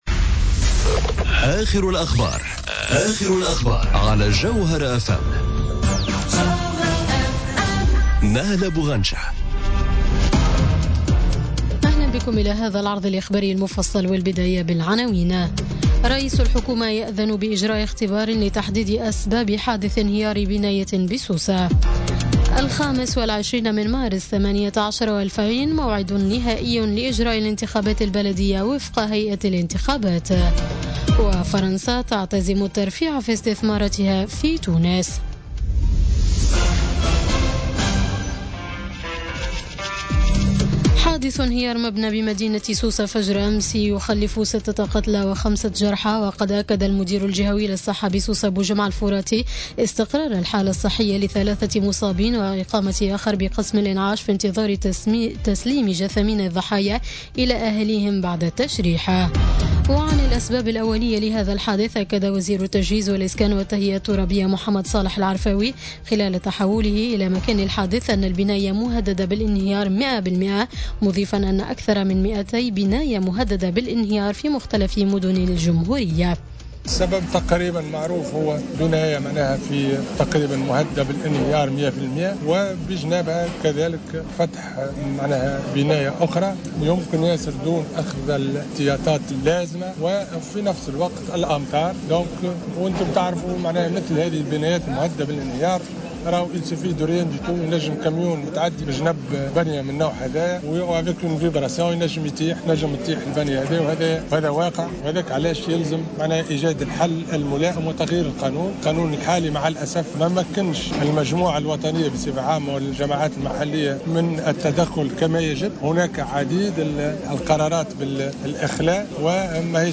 نشرة أخبار منتصف الليل ليوم الجمعة 6 أكتوبر 2017